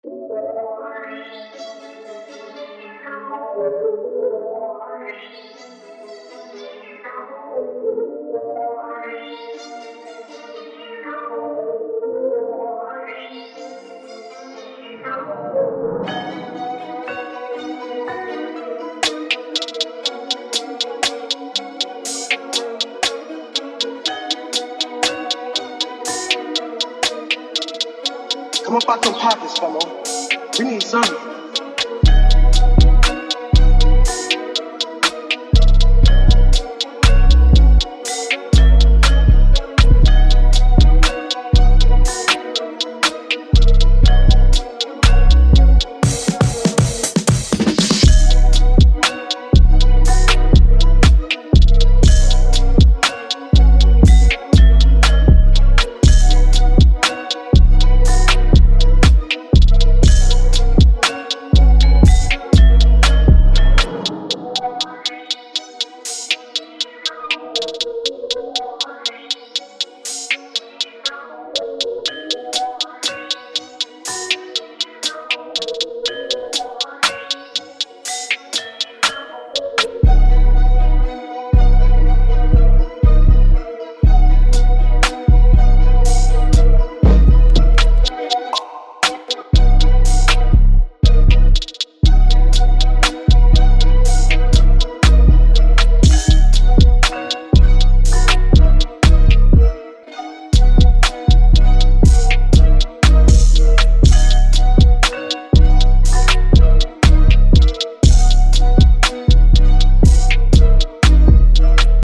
Dark/Psychedelic Trap
beats